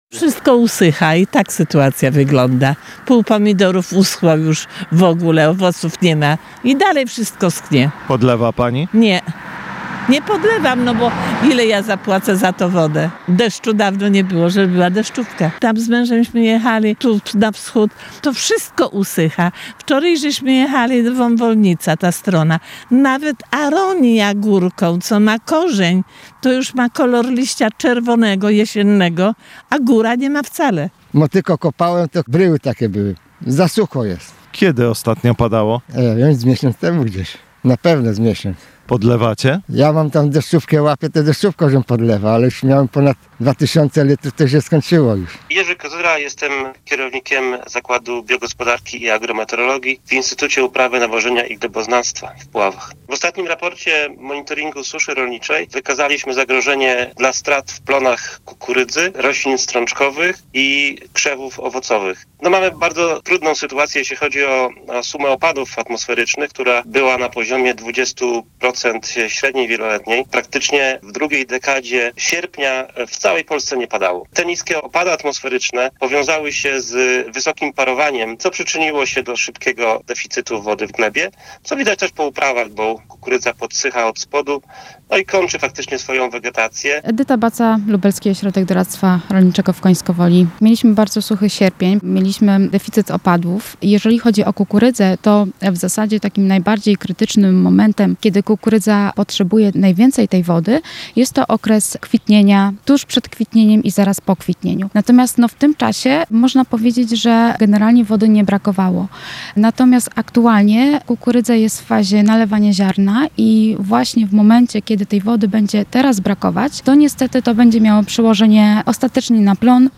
– Widać ją nie tylko w uprawach kukurydzy, ale także w przydomowych ogródkach – mówią mieszkańcy powiatu puławskiego.